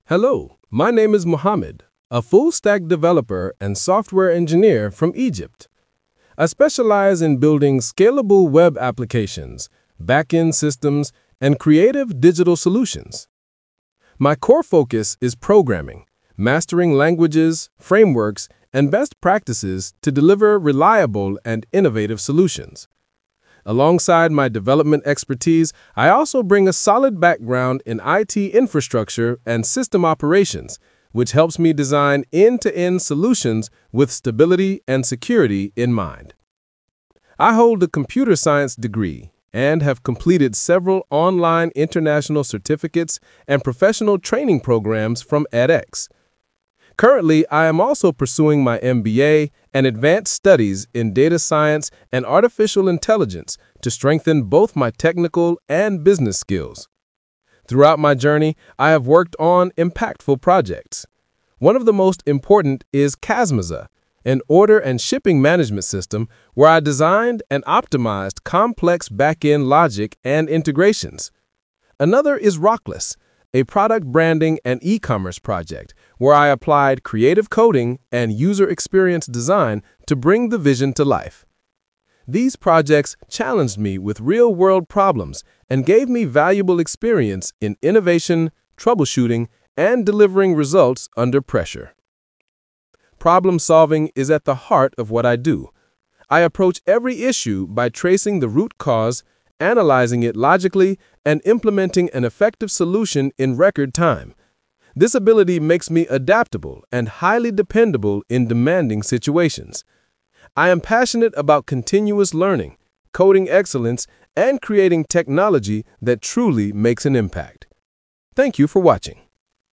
Get to know me in a quick audio introduction!
bio_about_me.mp3